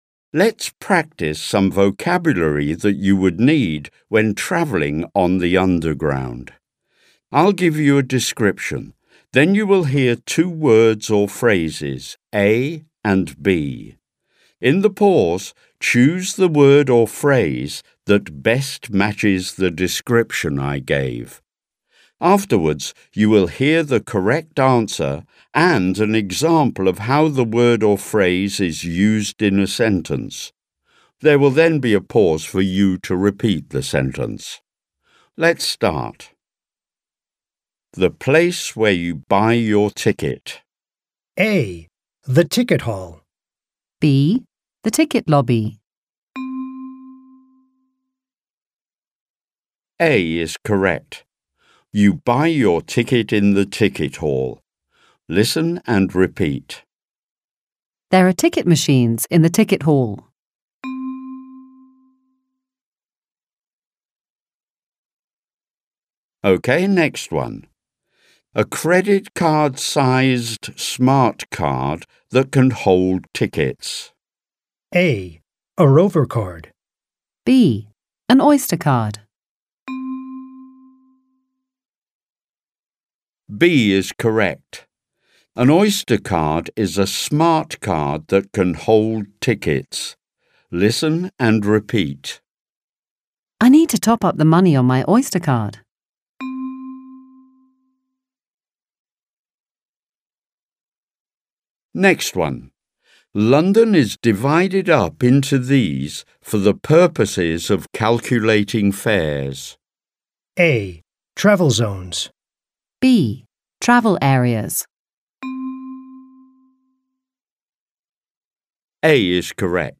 Exercise: Vocabulary | ZSD Content Backend
Audio-Übung